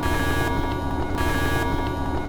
alarm2.ogg